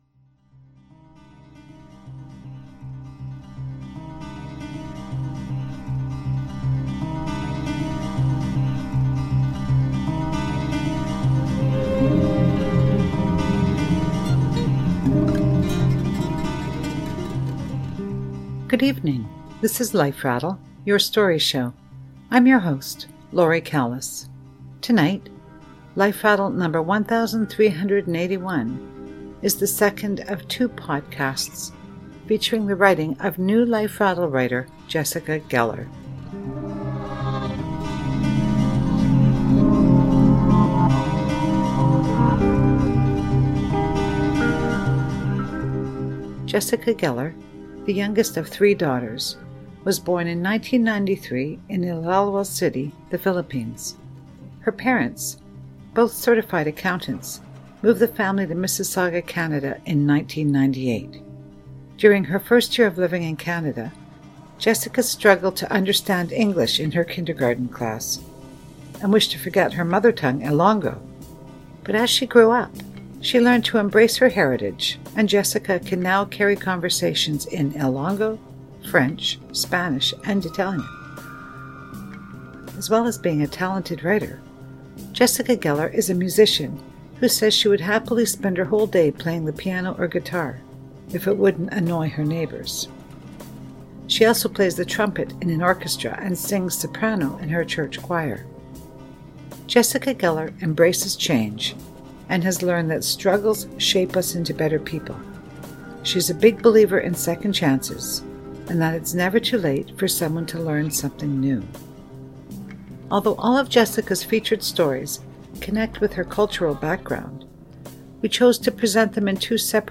Tonight’s stories are narrated by a young girl who shows us her experiences as she journeys to and settles into a new life in Canada with her mom, her dad and her sisters.